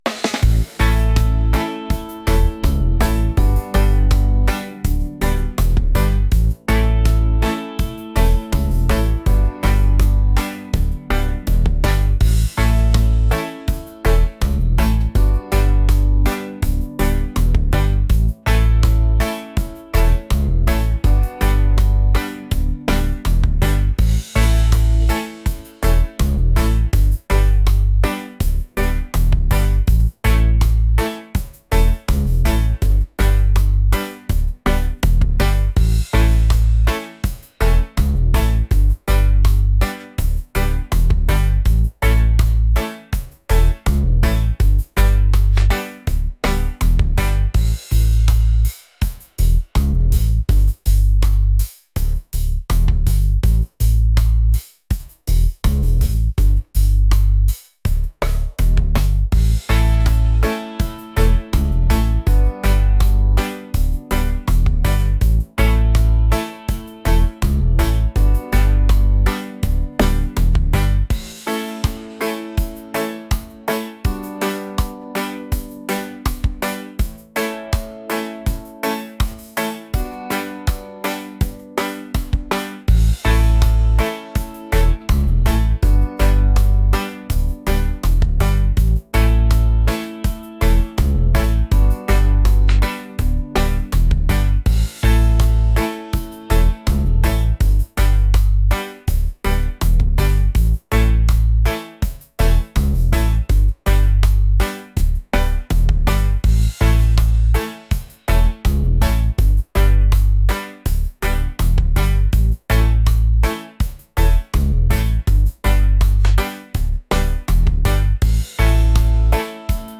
laid-back | upbeat | reggae